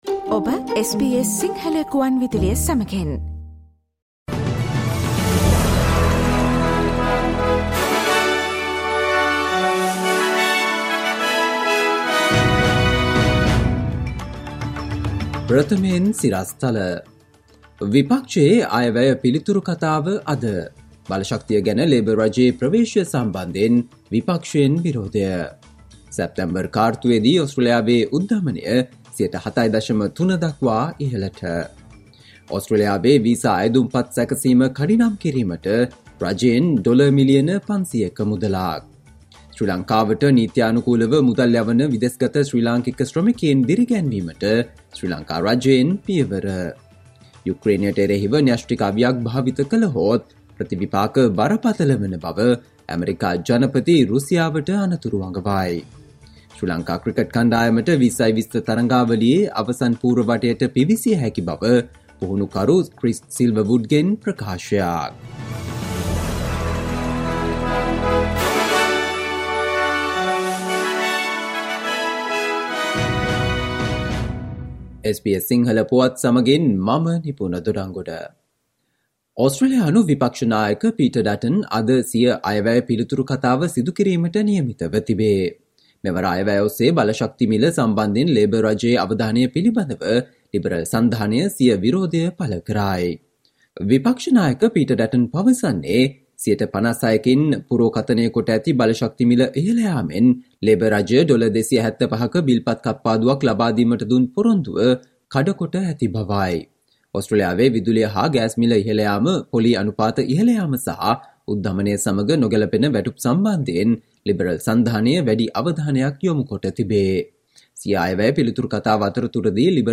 Listen to today's Sinhala news on SBS Radio - Thursday 27 October